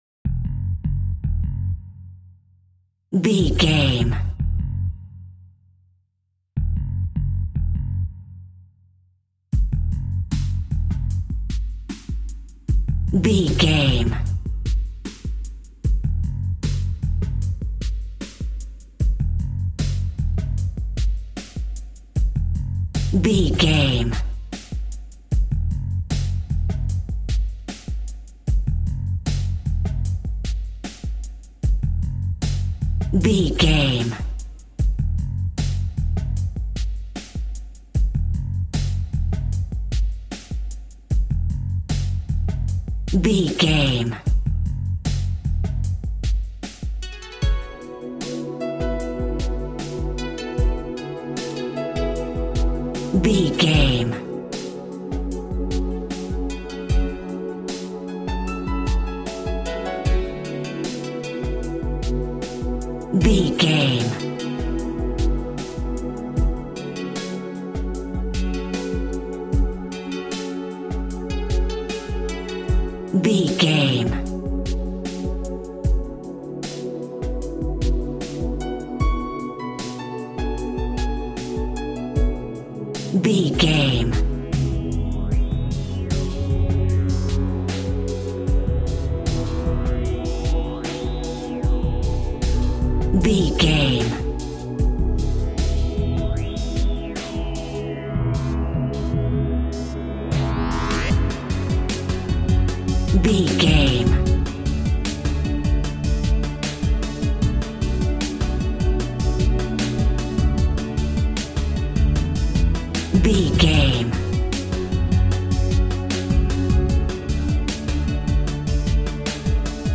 On Hold Music Cue.
Aeolian/Minor
F#
Fast
futuristic
hypnotic
dreamy
meditative
drums
bass guitar
electric guitar
piano
synthesiser
ambient
electronic
downtempo
pads
strings
drone